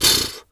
zebra_breath_03.wav